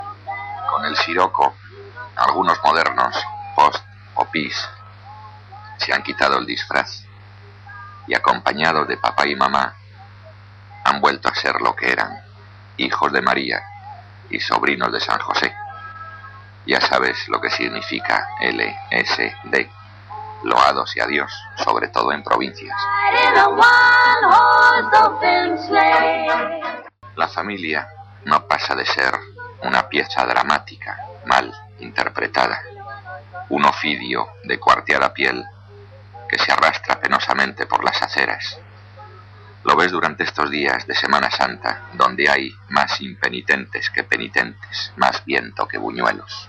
Un parell de refelxions postmodernes Gènere radiofònic Entreteniment